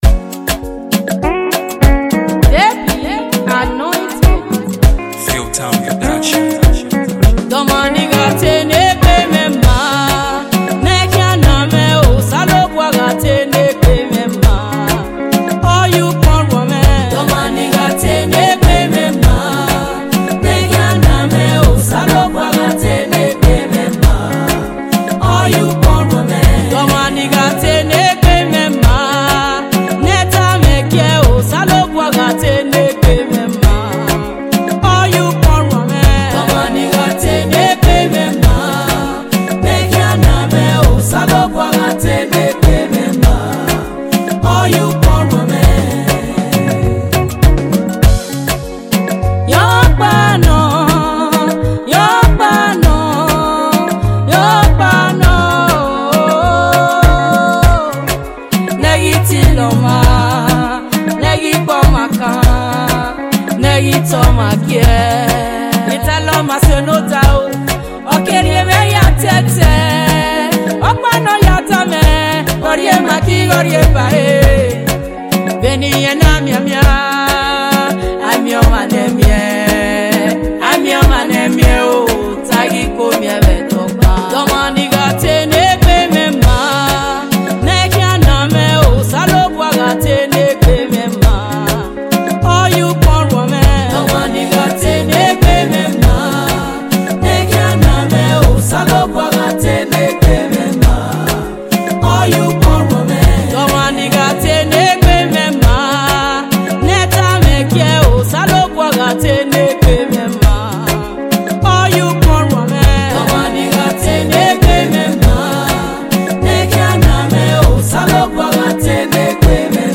African praisemusic